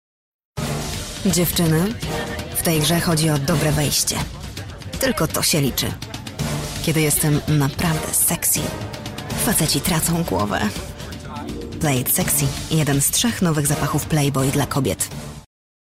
Female 30-50 lat
Demo lektorskie
Spot reklamowy